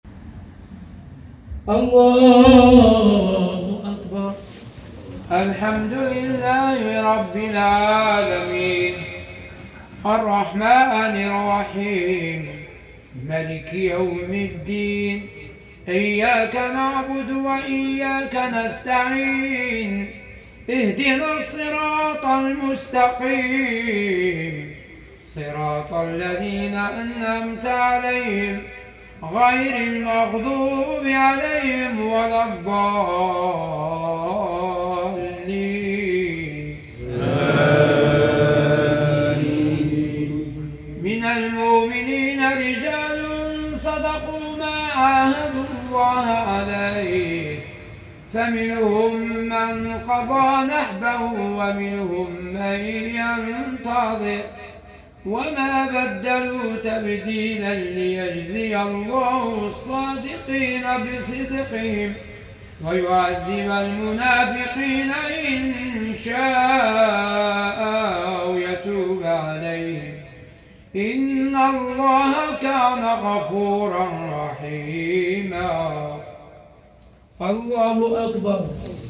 تلاوة ندية وجميلة